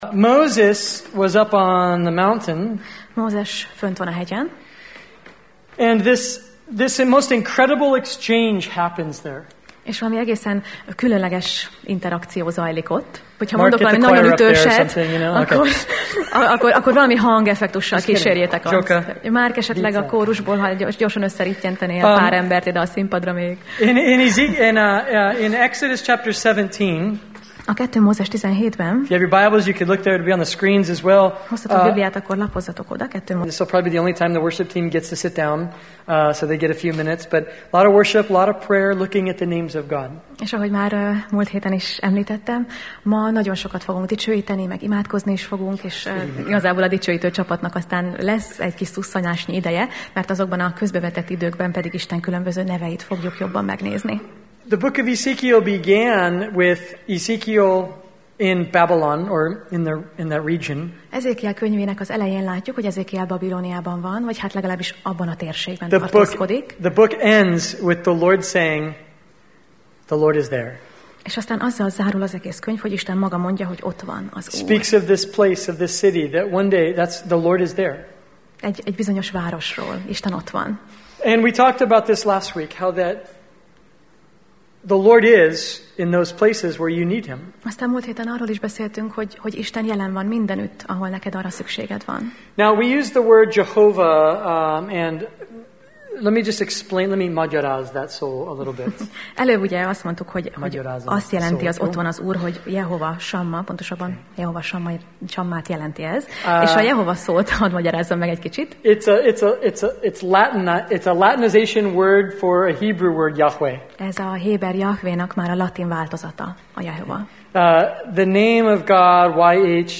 Sorozat: Ezékiel Alkalom: Szerda Este